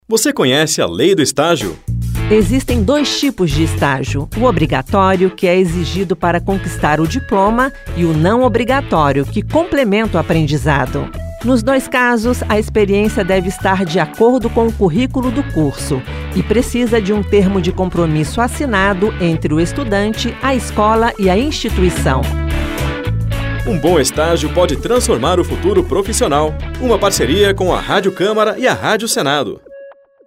Esta campanha da Rádio Câmara e da Rádio Senado traz cinco spots de 30 segundos sobre a Lei do Estágio: seus direitos, obrigações e os principais pontos da lei.